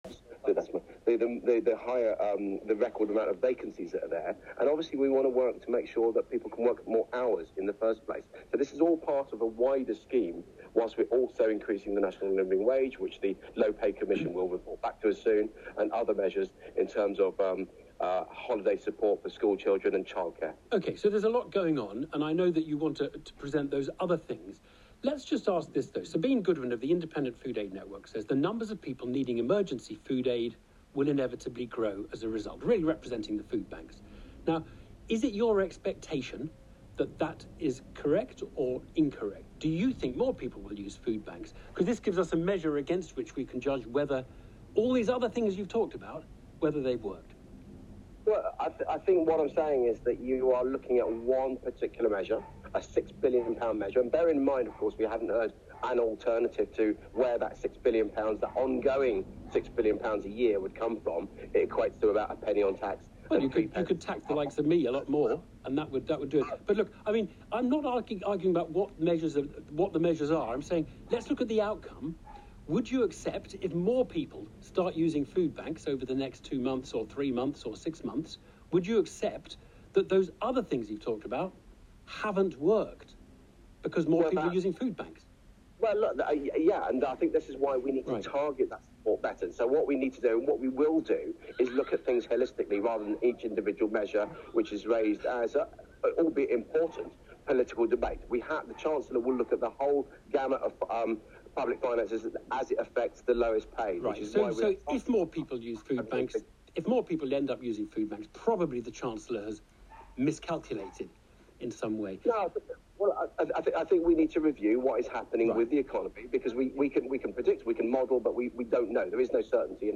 Evan Davis interviews Paul Scully - BBC Radio 4 PM, 6.10.21